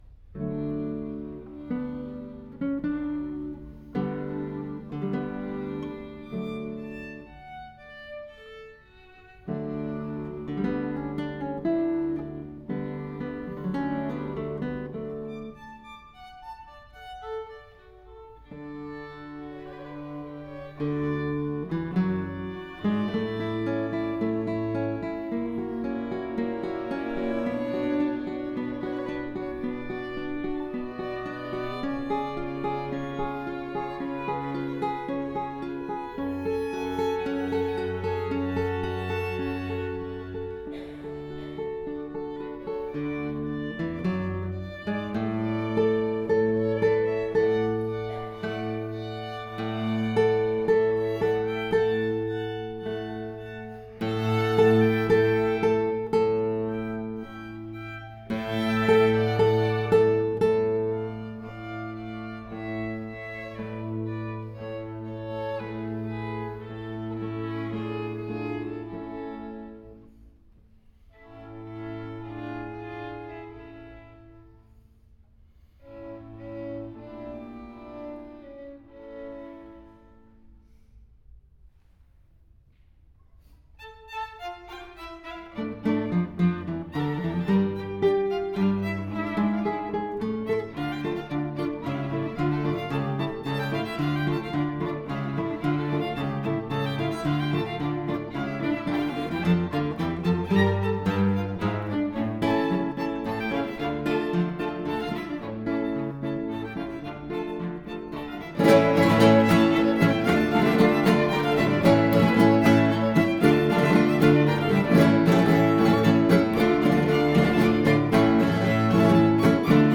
guitar.
Galatea Quartet.